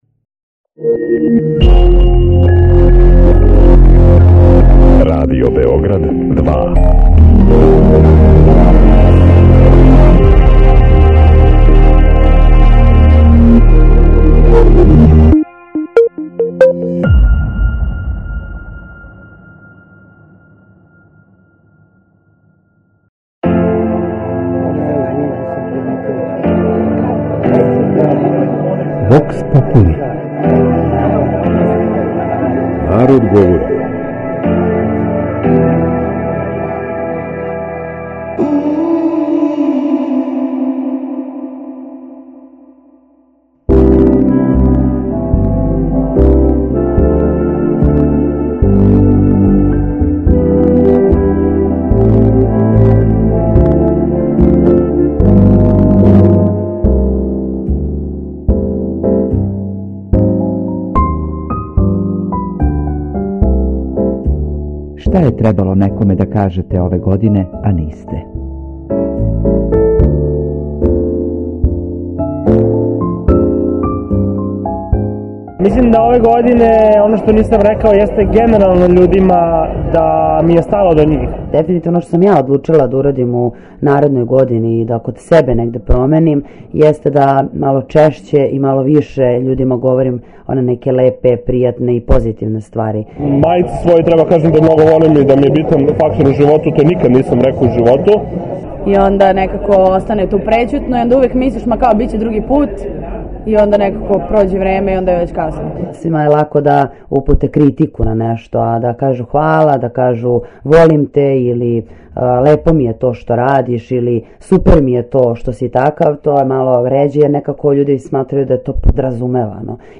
У данашњој емисији наши саговорници имаће прилике да изговоре оно што се нису усудили да изговоре читаве 2018. године. Данашње питање гласи: "Шта је требало некоме да кажете ове године, а нисте?"